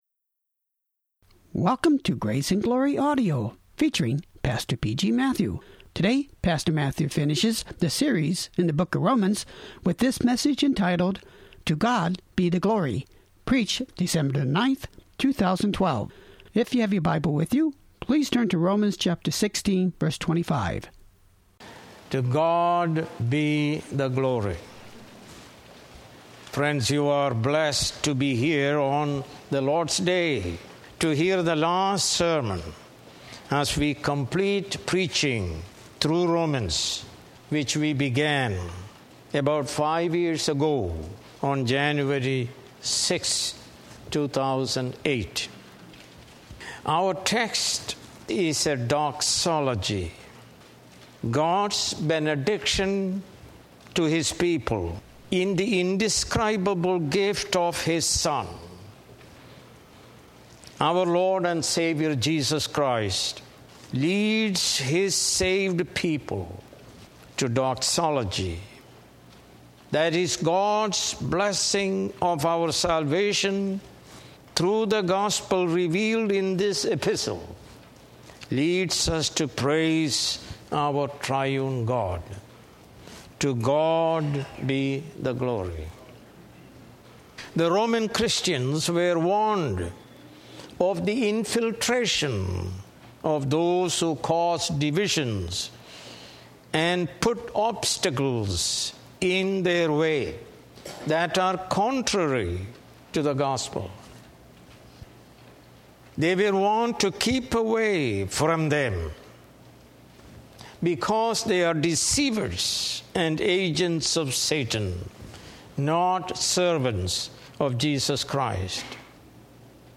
More Sermons From the book of Romans